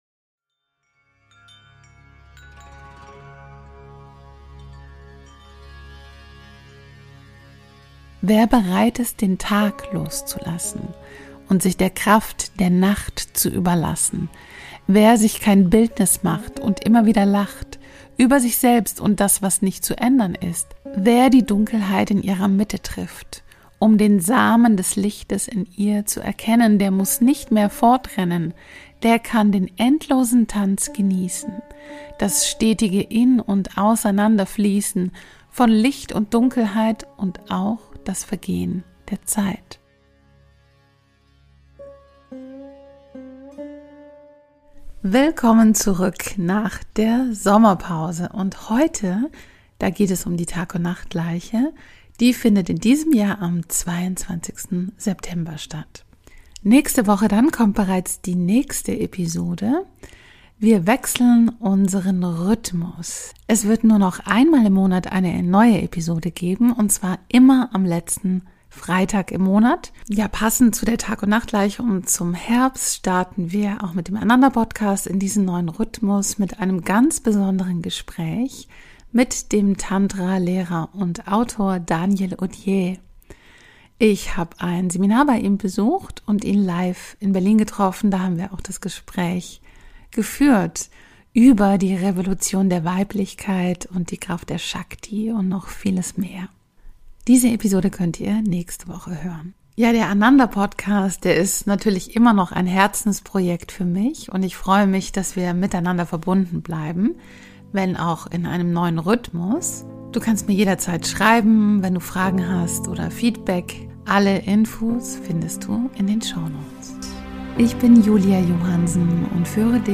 Impulse für die Tagundnachtgleiche im Herbst & eine sinnliche Meditation für die Balance